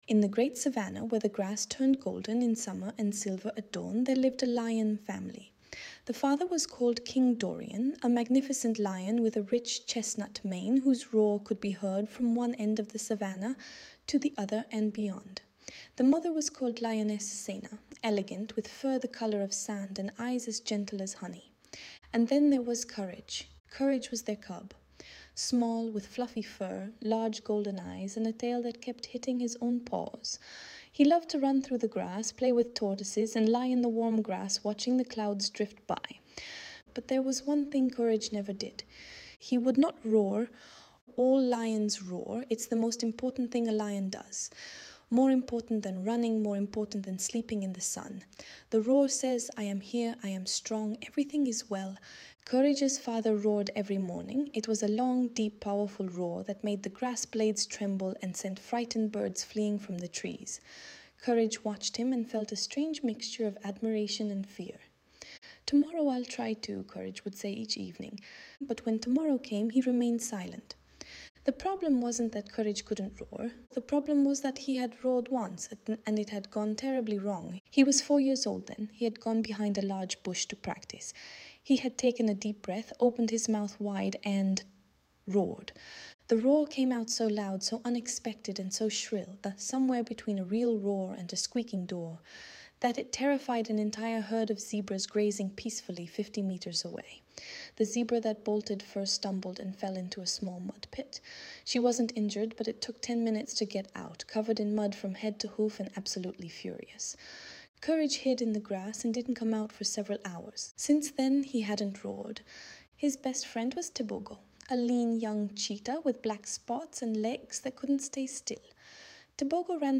audiobook The Courageous Lion Who Refused to Roar - A Heartwarming Tale of True Bravery
Audiobook-The-Courageous-Lion-Who-Refused-to-Roar-A-Heartwarming-Tale-of-True-Bravery.mp3